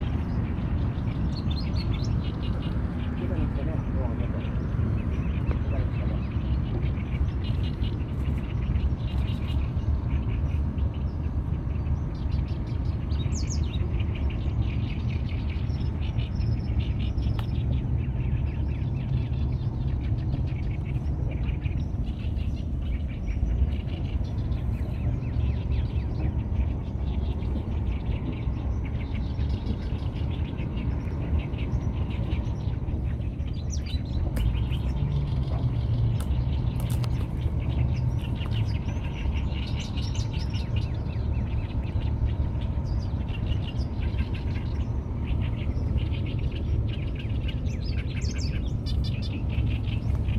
They all have a characteristic call.
It requires much more experience or use of sonogram to ID them by song, but a few of them (Alström’s especially) include their call in their song, and some of them (Grey-crowned, Martens’s) include a trill in their song.
warbler-alstrom001-Seicercus-soror.mp3